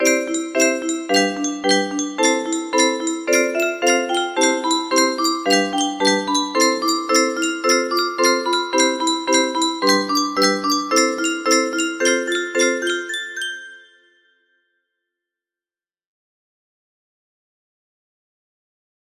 10219 music box melody
Grand Illusions 30 (F scale)